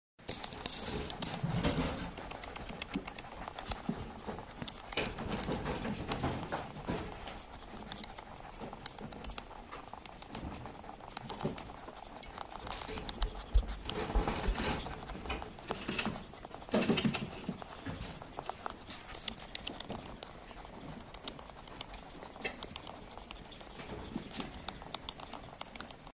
Звуки кролика
Кроль воет